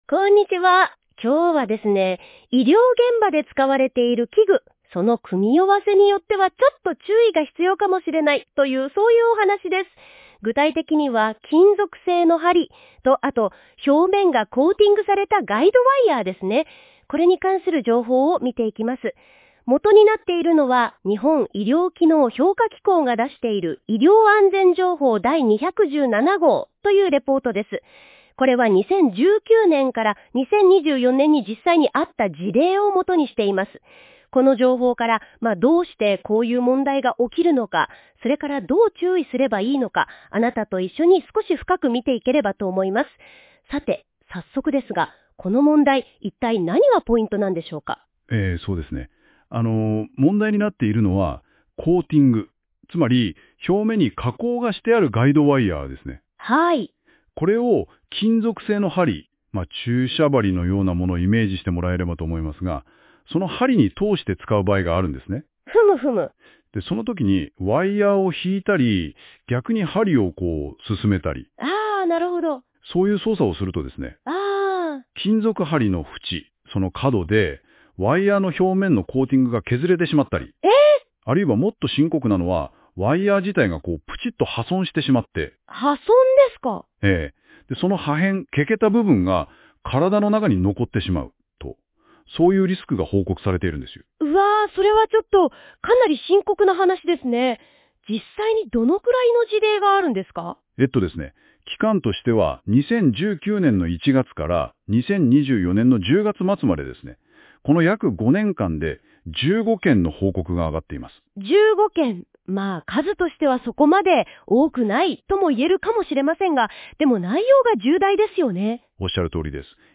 当法人では、医療機能評価機構が発行する「医療安全情報」について、理解を深めていただくための音声解説を配信しております。なお、本ページに掲載している音声は、AIによる自動音声合成で作成しています。そのため、一部に読み方やイントネーションなど不自然に感じられる箇所がありますが、あらかじめご了承ください。